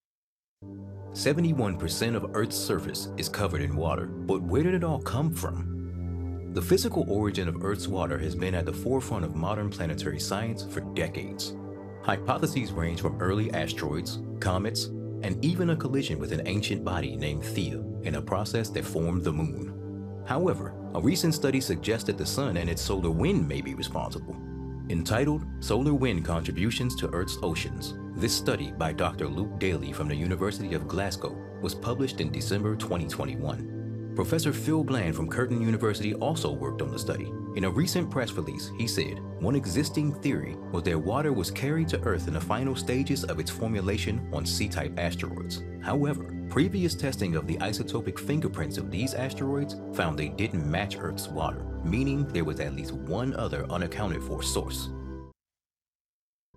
Powerful, Motivating, Best Buddy
Documentary